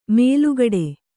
♪ mēlugaḍe